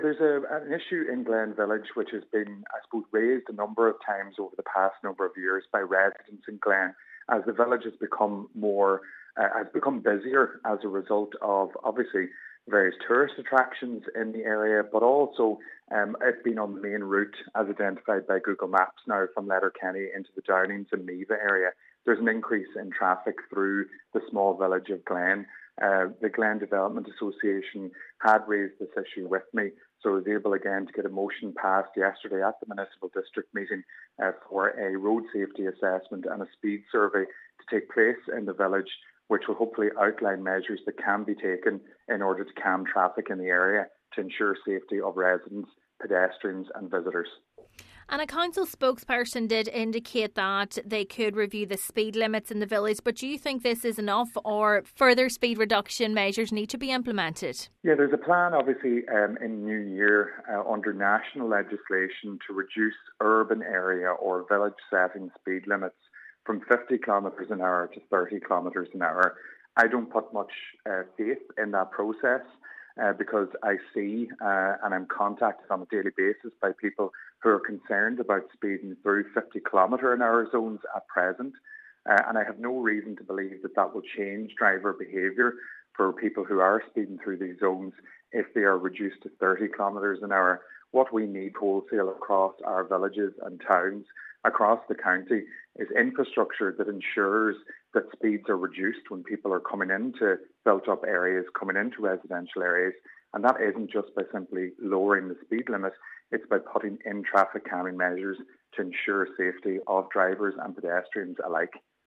Councillor Declan Meehan however, believes additional efforts need to be made to slow down traffic through Glen: